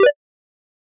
フリー効果音：システム３
フリー効果音｜ジャンル：システム、システム系効果音の第３弾！